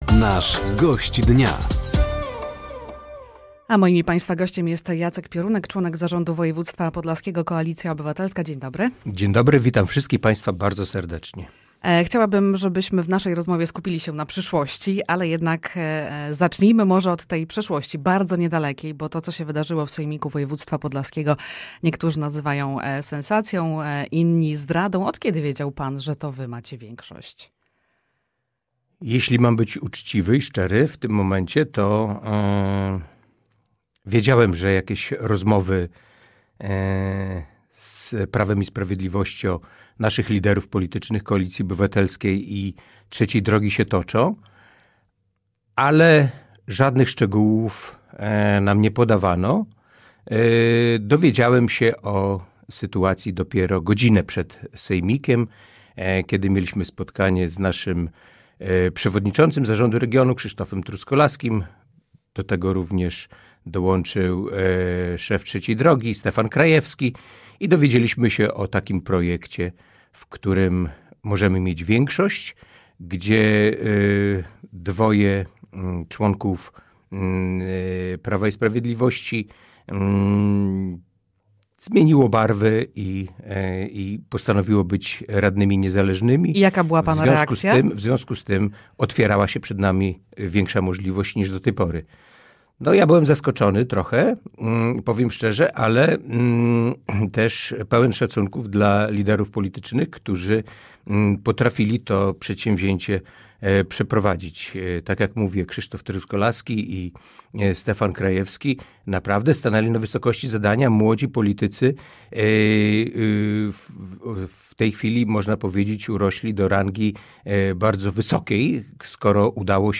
Gościem Dnia Radia Nadzieja był Jacek Piorunek, członek zarządu Województwa Podlaskiego.